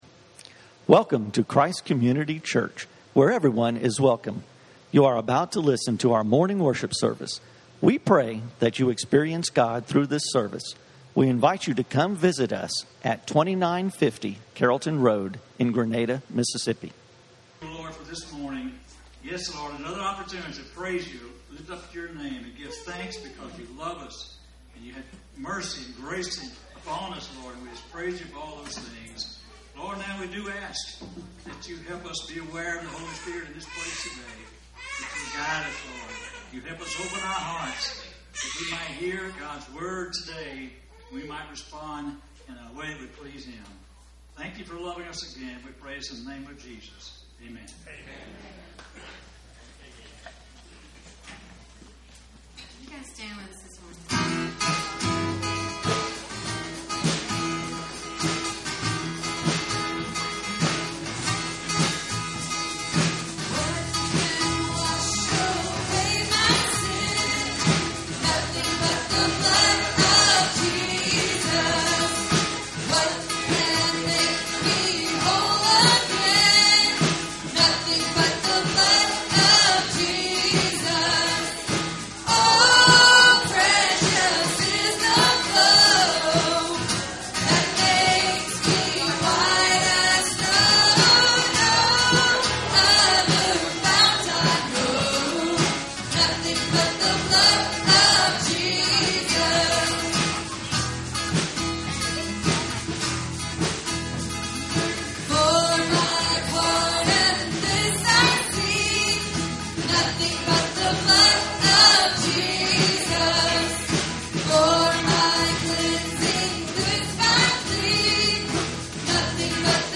Praying Circles - Messages from Christ Community Church.